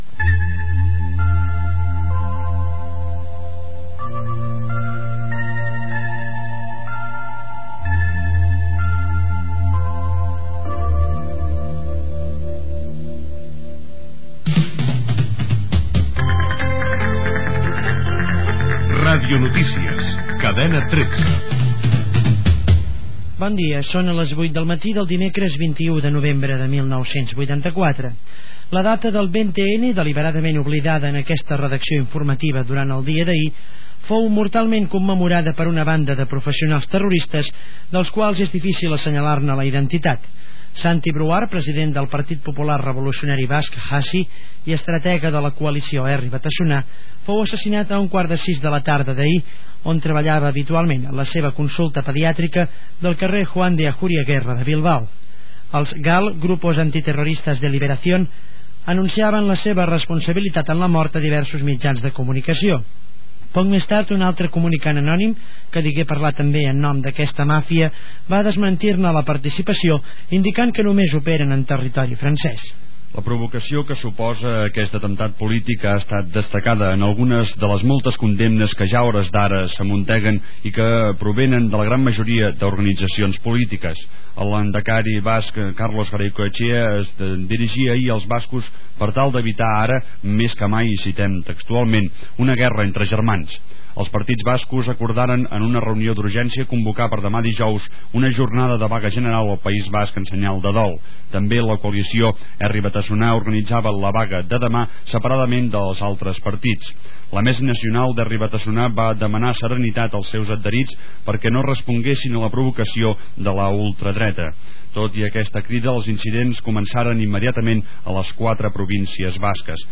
Sintonia de la cadena, careta del programa, data, informació, a l'endemà, de l'assassinat del dirigent de l'esquerra abertzale Santi Brouard al País Basc pel GAL (Grupos Antiterroristas de Liberación)
Informatiu